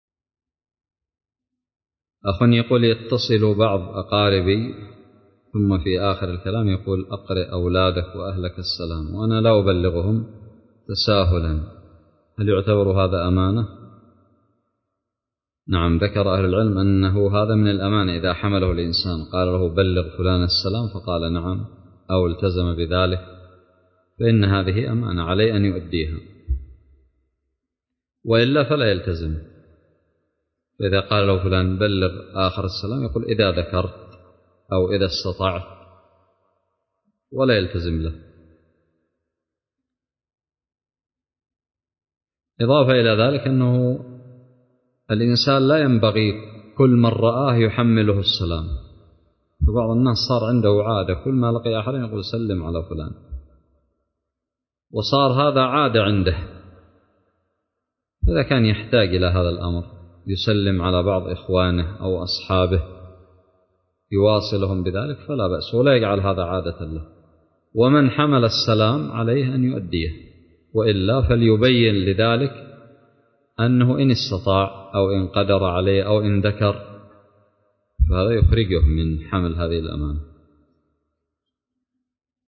:العنوان فتاوى عامة :التصنيف 1447-4-4 :تاريخ النشر 42 :عدد الزيارات البحث المؤلفات المقالات الفوائد الصوتيات الفتاوى الدروس الرئيسية يتصل بعضهم ويقول أقرء فلان السلام وأنا لا ابلغهم تساهلا؟